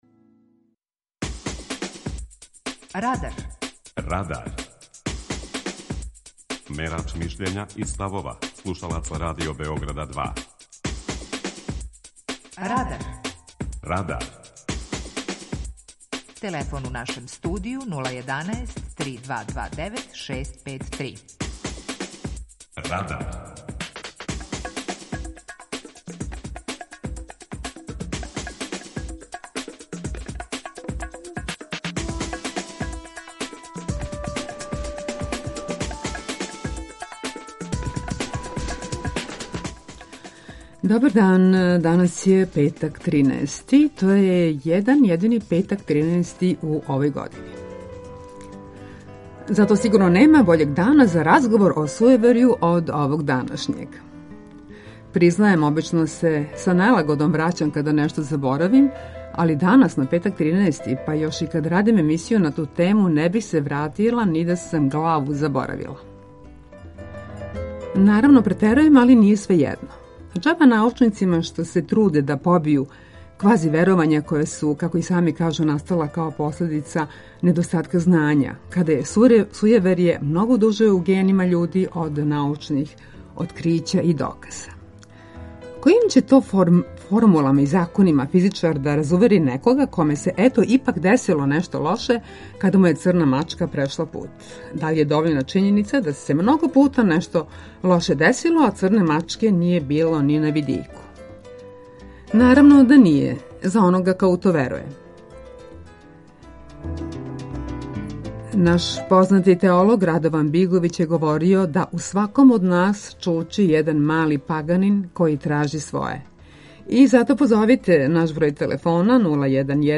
Питање Радара је: Колико смо сујеверни? преузми : 17.46 MB Радар Autor: Група аутора У емисији „Радар", гости и слушаоци разговарају о актуелним темама из друштвеног и културног живота.